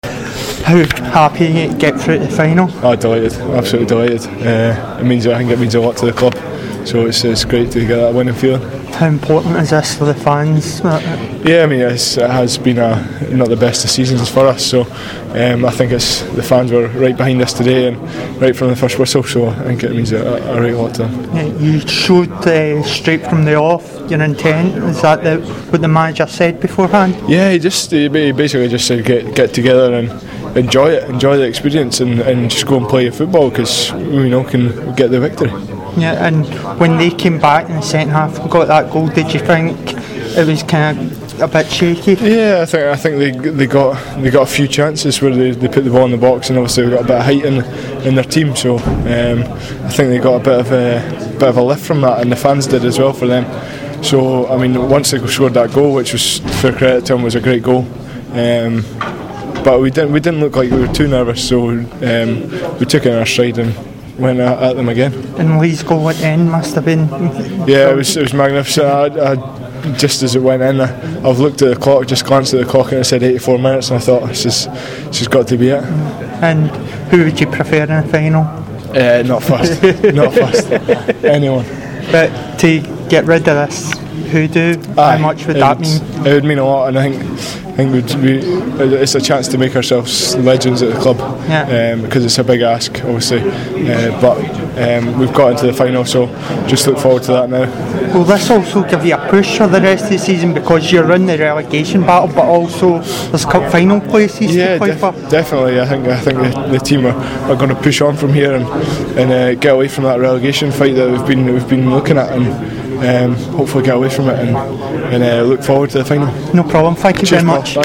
David Wotherspoon speaks post-match after Hibs win the Scottish Cup semi-final against Aberdeen